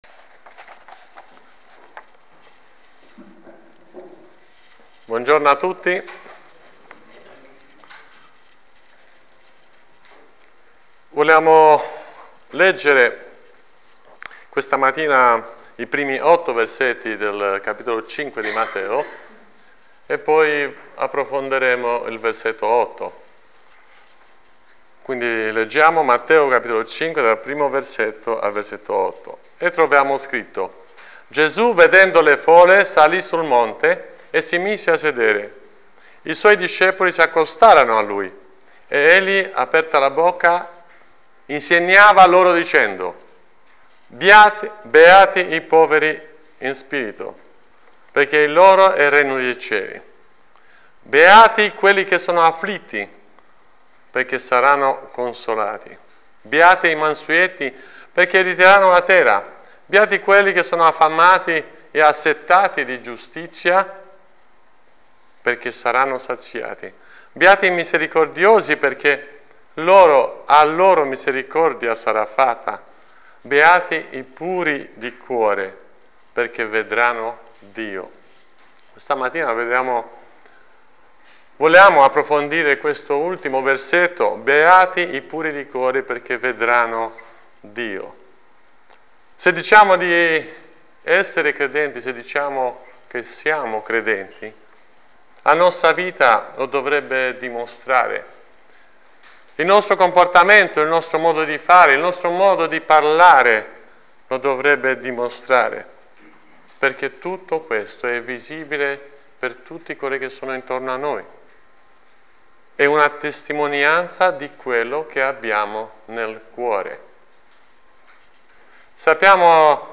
Insegnamenti dal passo di Matteo 5:8